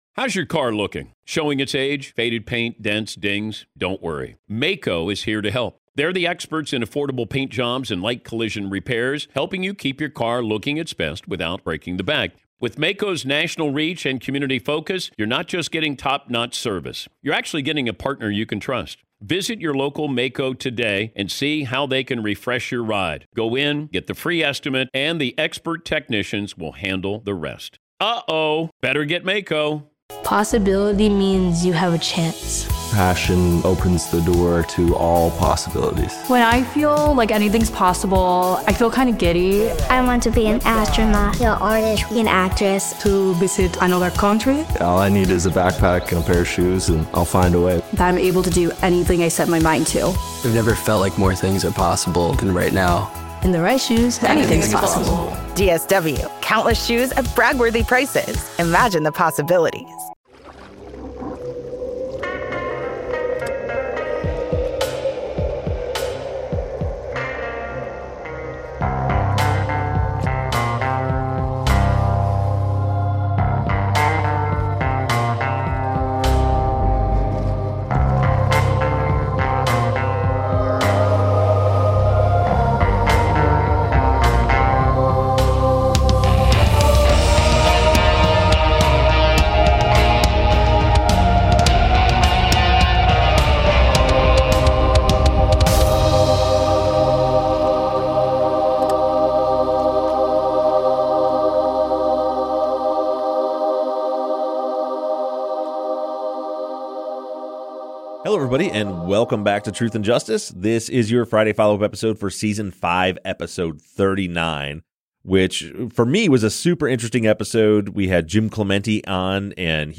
The guys make another remote episode